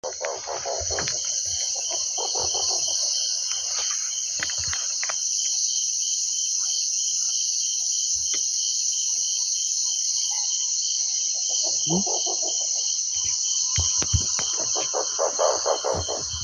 Rusty-barred Owl (Strix hylophila)
Location or protected area: Parque Nacional Iguazú
Condition: Wild
Certainty: Recorded vocal
lechuza-listada-101-64.mp3